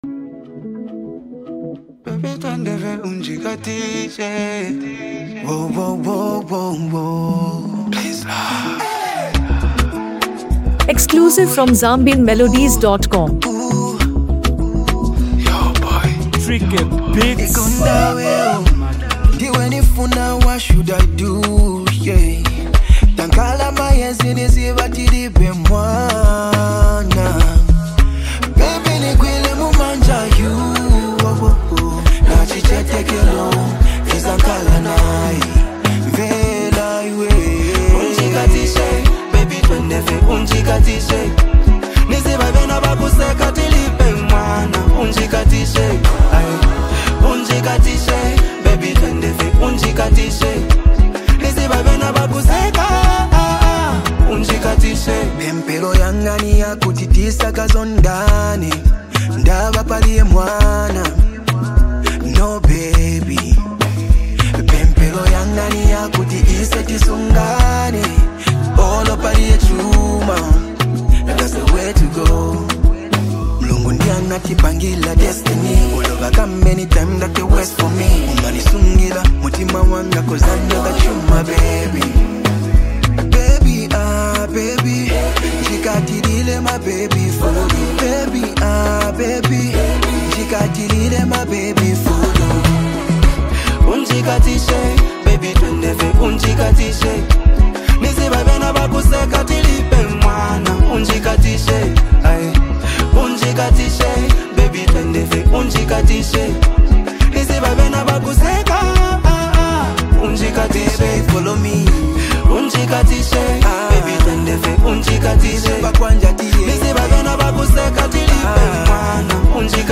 is a melodious Afro-pop love song